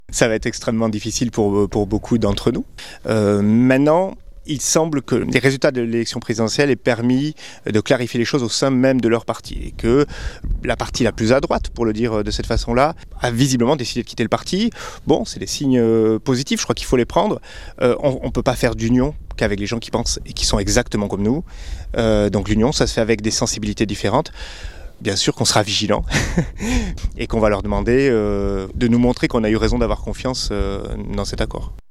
70 circonscriptions devront faire campagne pour un candidat socialiste pour les législatives comme le prévoit l’accord passé entre LFI et le PS. Un Reportage